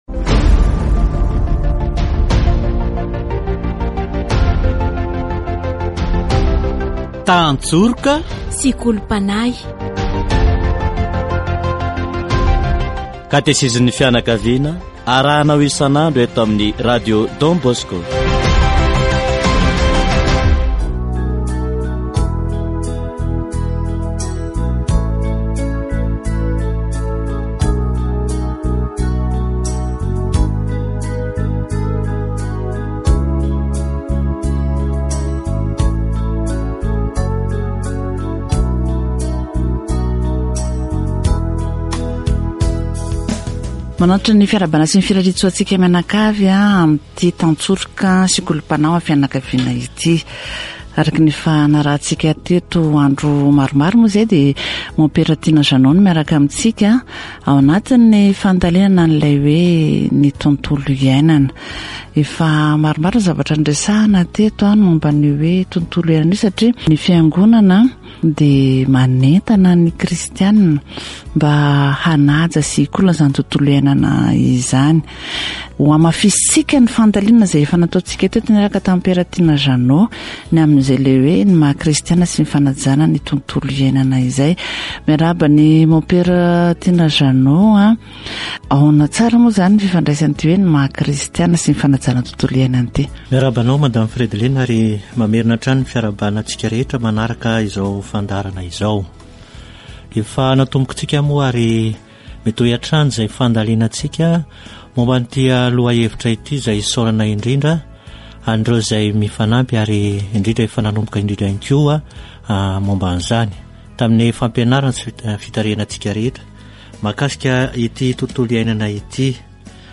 Katesizy momba ny tontolo iainana